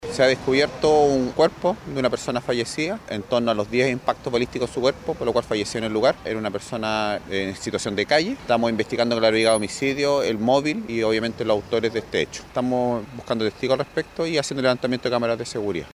El fiscal jefe regional del Sistema de Análisis Criminal y Focos Investigativos, José Uribe, afirmó que la víctima fue herida en al menos 10 oportunidades. Ahora se trabaja en ubicar testigos y encontrar cámaras de seguridad.